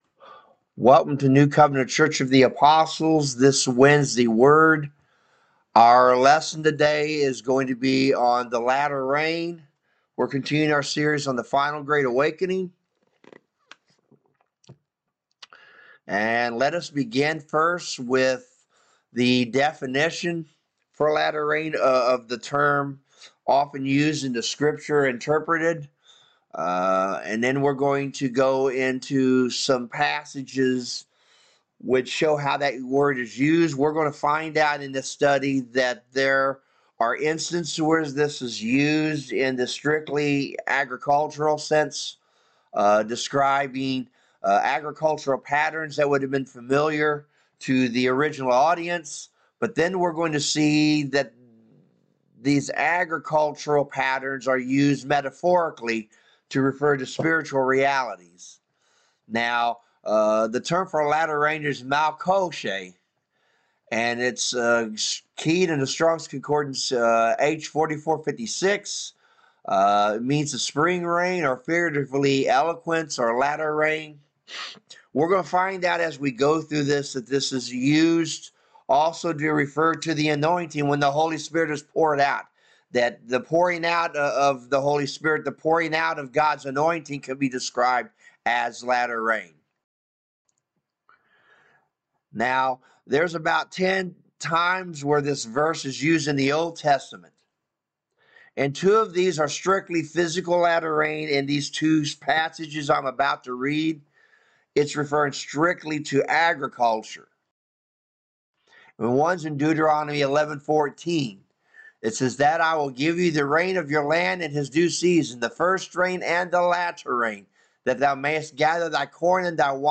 Wednesday Word Bible Study